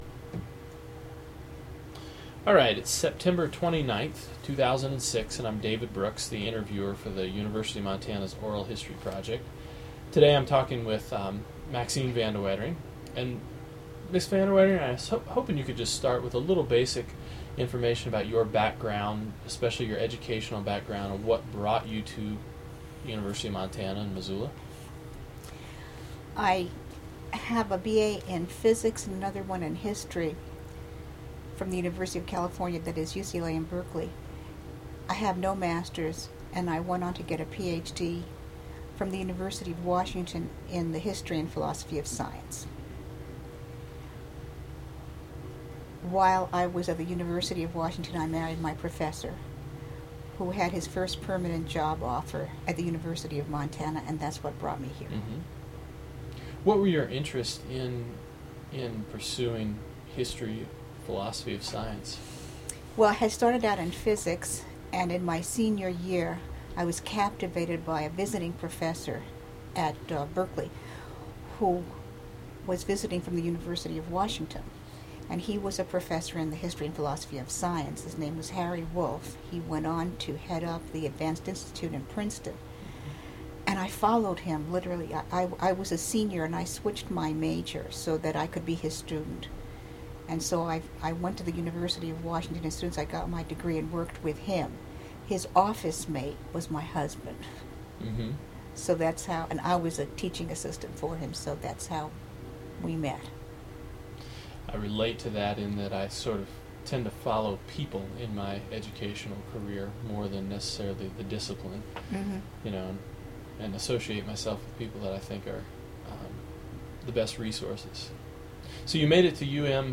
Document Type Oral History